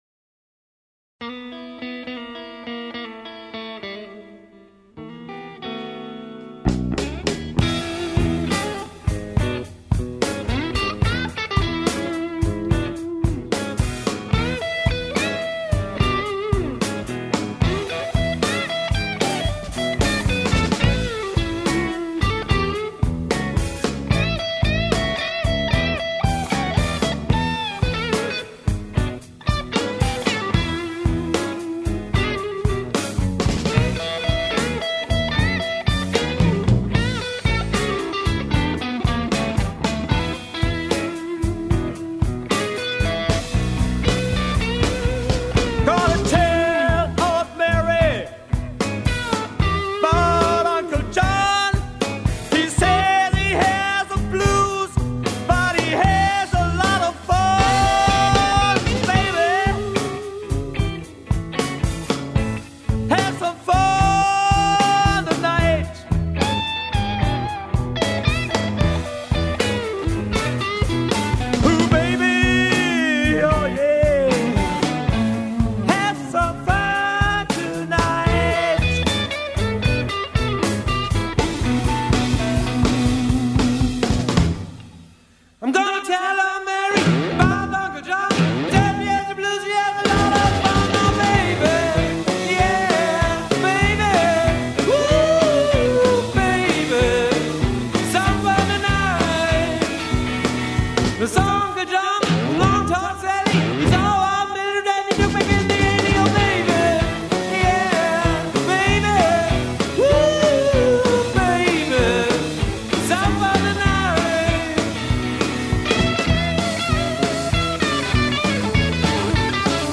Rock der 60ger und 70ger
mit mehrstimmigen Satzgesang ausgestatteten Coversongs
Auftritt Stadtfest Ronnenberg